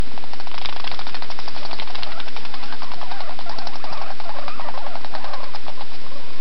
Vocalization
• Chutting and whining are sounds made in pursuit situations by the pursuer and pursuee, respectively.
Guinea_Pig_Angry.ogg.mp3